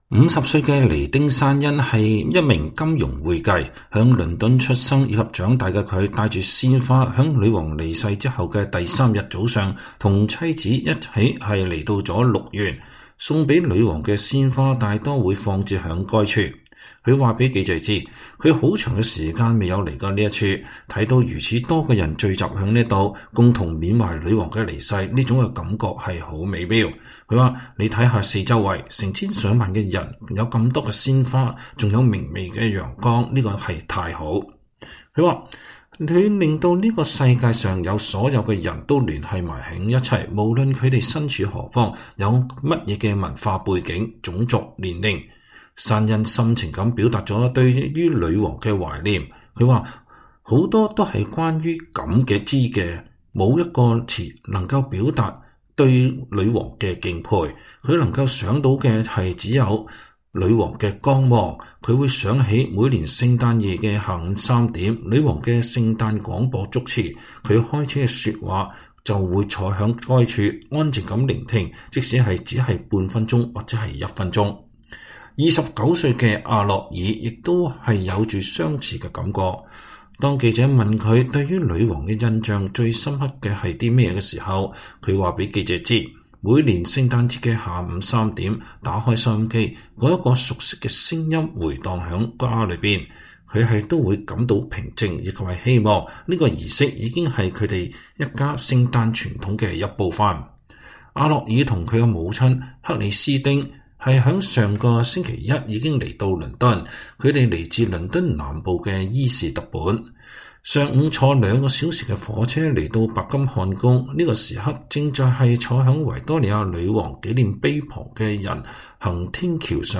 在倫敦的五天裡，我每天都出沒在白金漢宮周圍，進行了三四十個大小不一的街頭採訪。